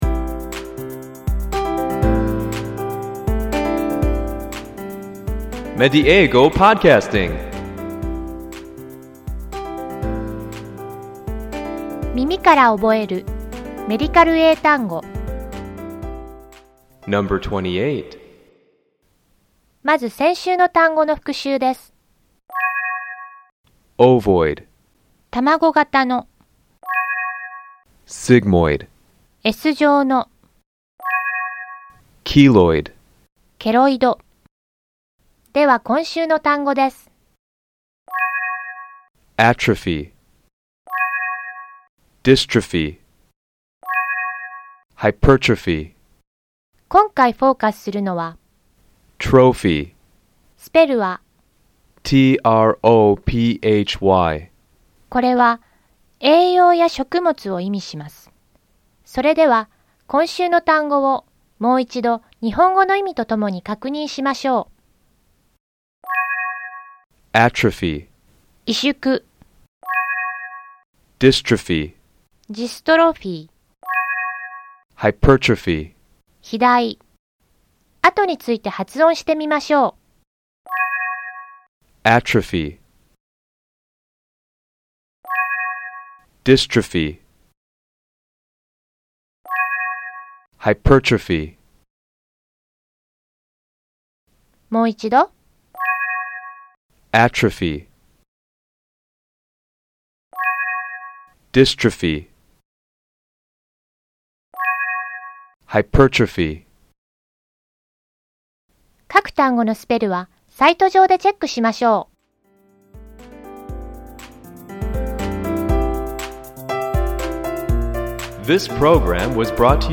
この「耳から覚えるメディカル英単語」では，同じ語源を持つ単語を毎週3つずつ紹介していきます。ネイティブの発音を聞いて，何度も声に出して覚えましょう。